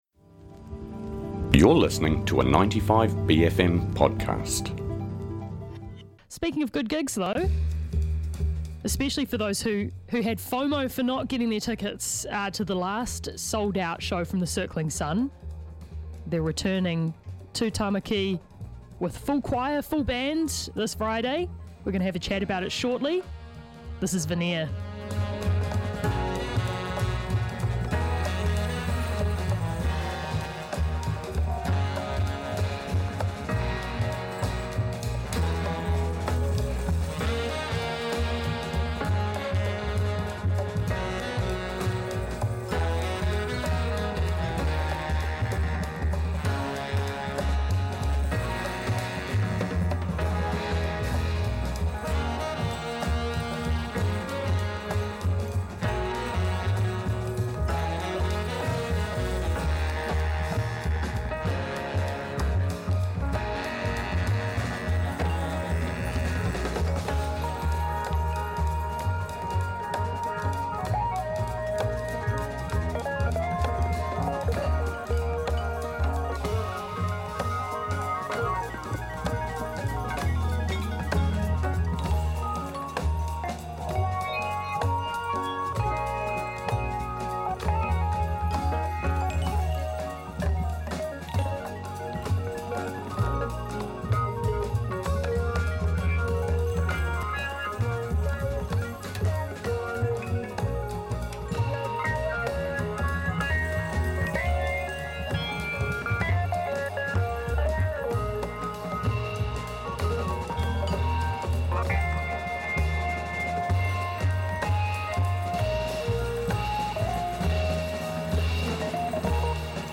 Guest Interviews
in the studio to talk about their show this Friday night at Whammy Bar, and play us a recording from their last show.